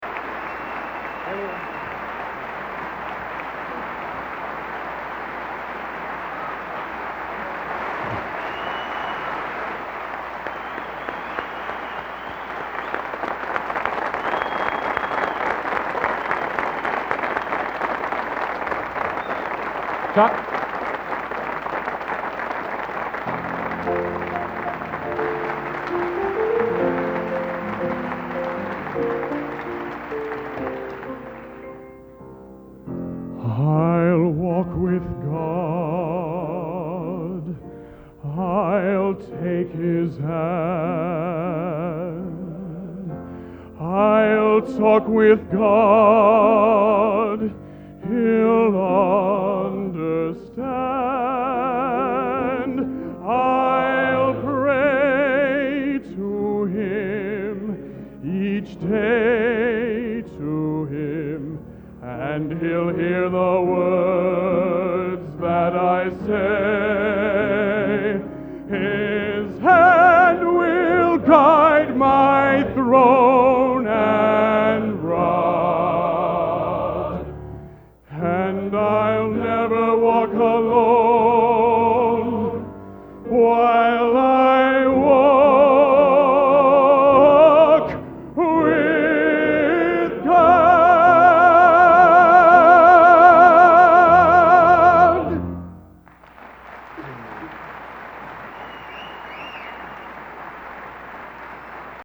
Location: West Lafayette, Indiana
Genre: Sacred | Type: End of Season